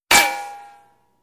dummyHeadHit.wav